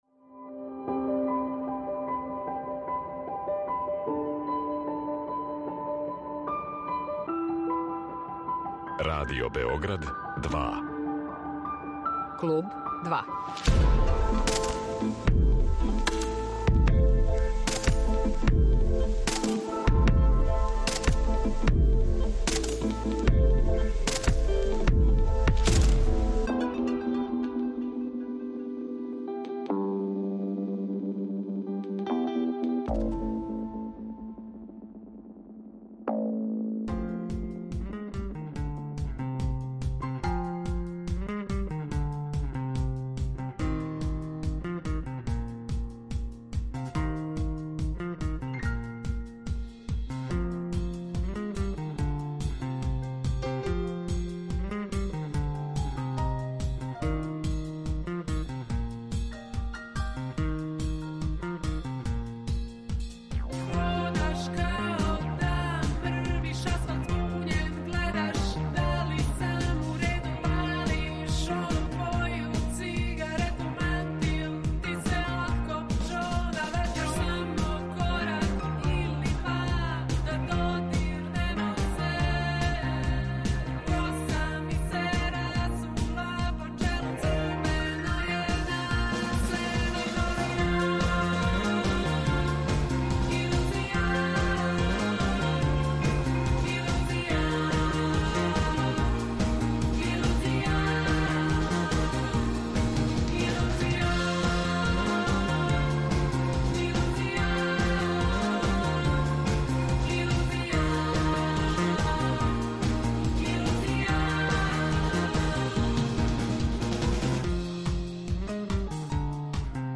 Гошћа данашњег Клуба 2 је Маја Цветковић, певачица и басисткиња бенда E-Play, који ће сутра 27. новембра у Дому омладине одржати концерт са гостима, пријатељима који су обележили каријеру овог београдског бенда.